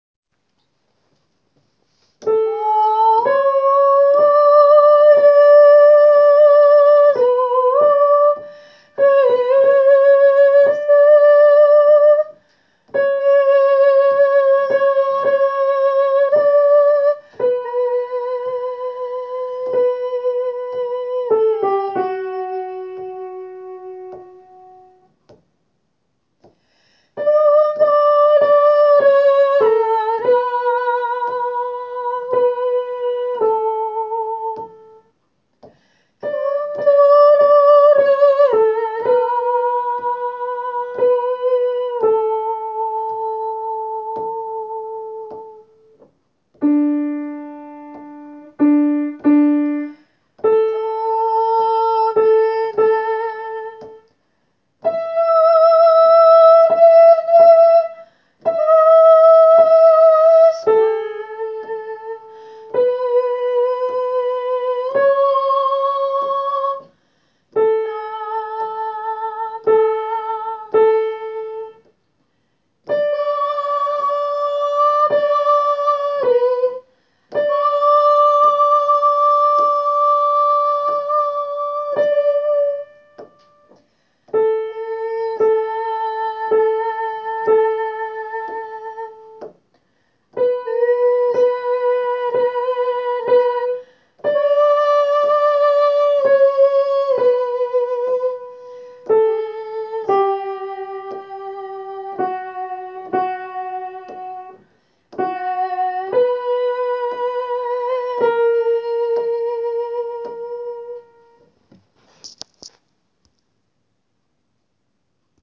Tenor :
jesu-christe-tenor.wav